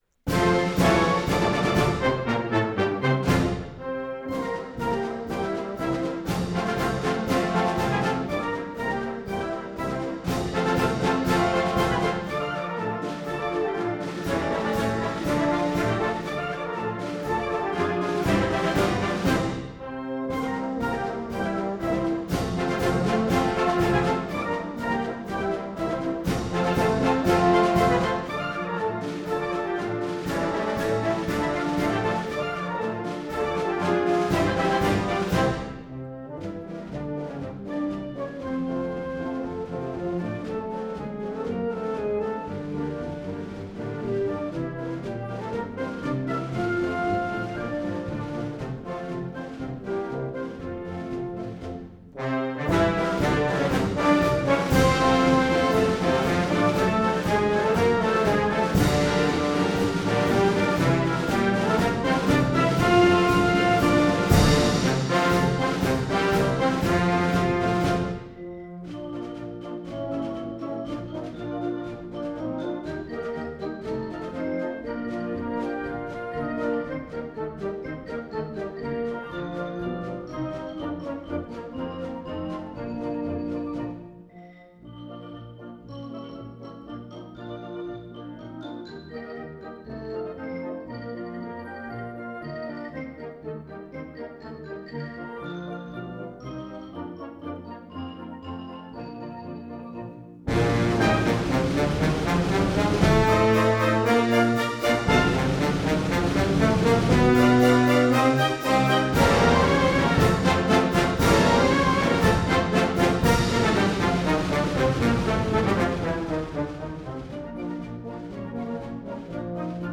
The Dauntless Battalion March from The Complete Marches of John Philip Sousa: Vol. 6